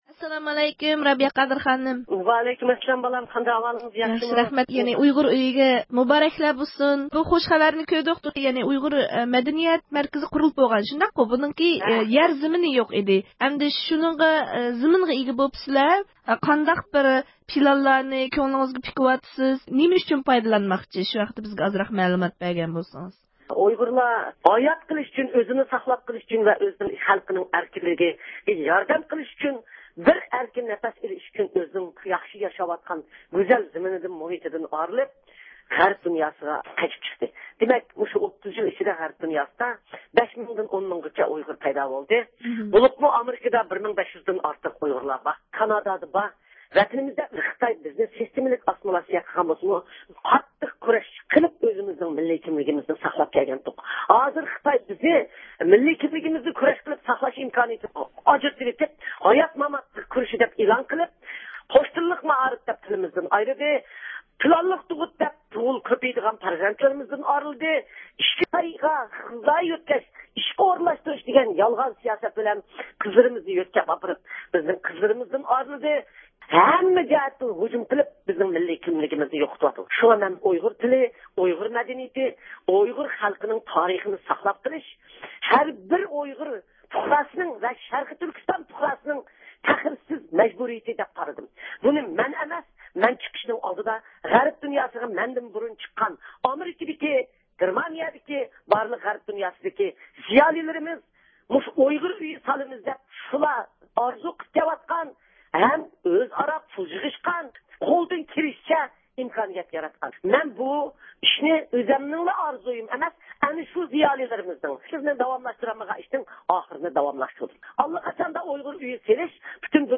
يۇقىرىدىكى ئاۋاز ئۇلىنىشىدىن مۇخبىرىمىزنىڭ ئۇلار بىلەن ئۆتكۈزگەن سۆھبىتىنى ئاڭلىغايسىلەر.